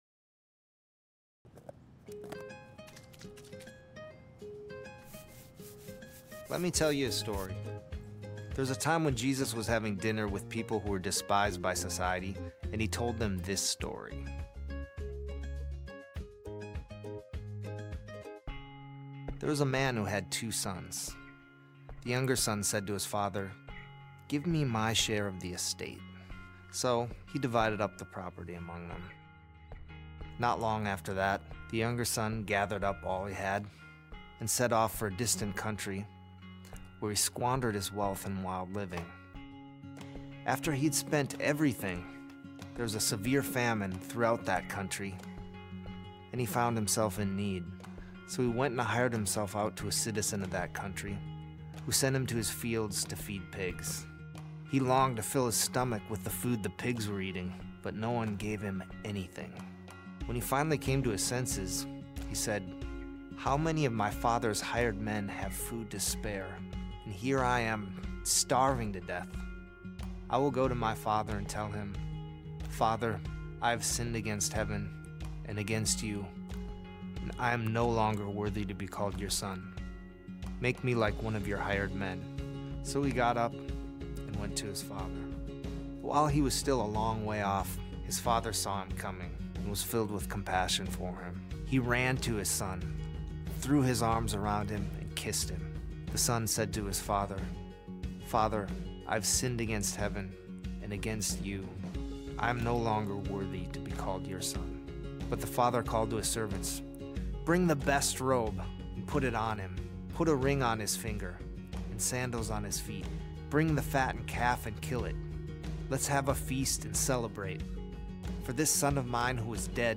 Sermon Reflections: How do you relate to either the younger or older son in the parable?